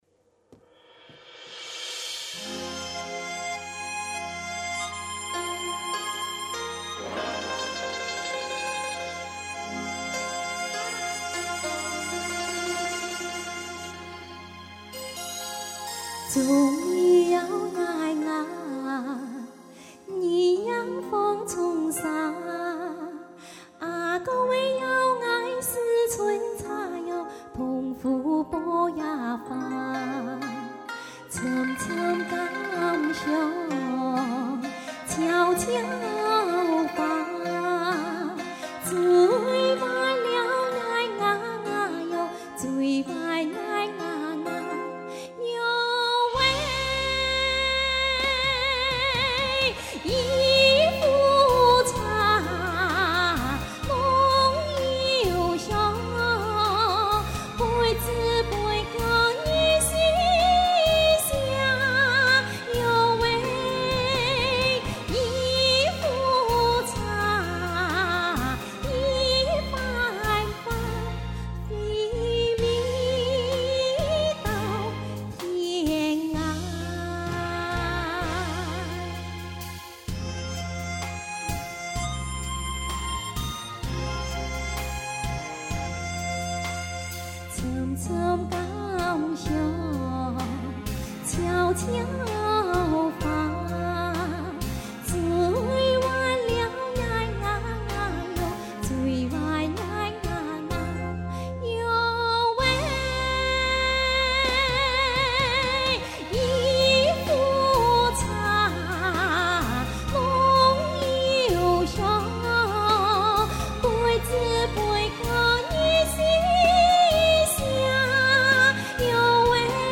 第二届客家新山歌演唱大赛十首山歌
回味》 - 客家传统歌曲